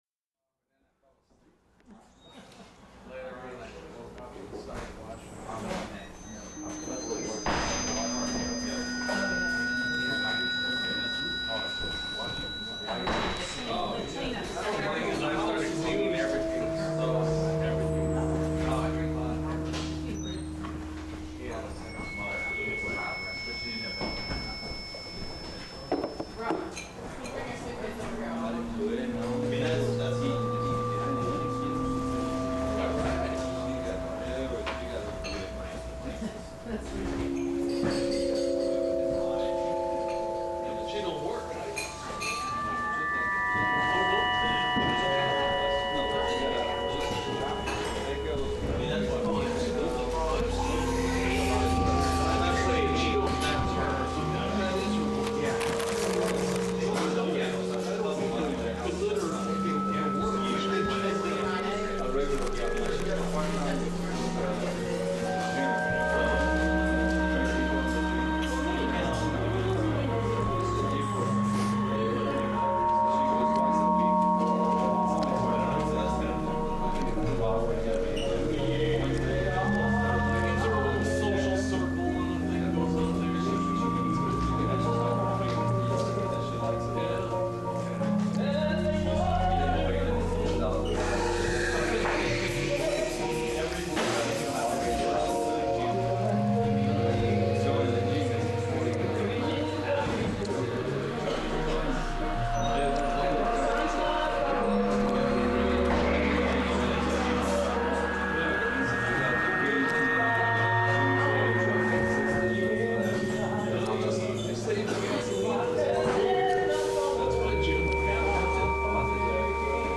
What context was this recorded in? I then recorded the system harmonizing with ambient sounds. A nice little cafe in Potrero Hill, a block away from my old loft. 5 blocks from my new place.